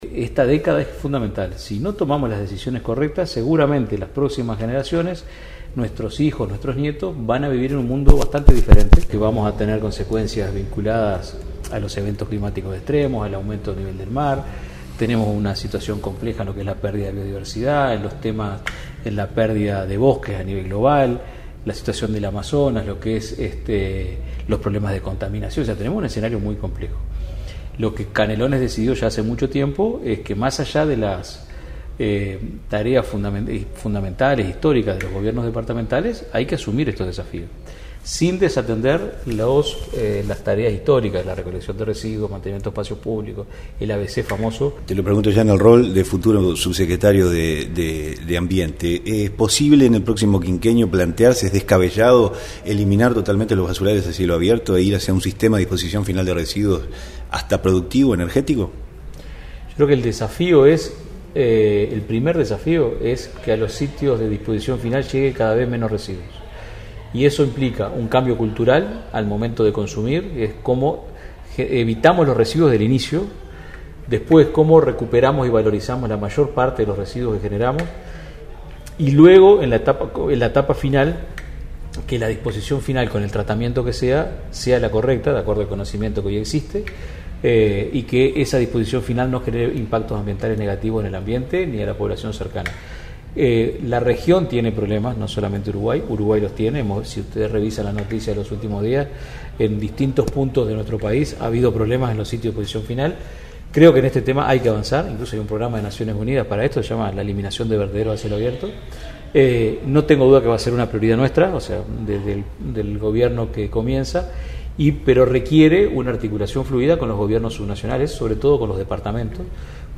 Informó el corresponsal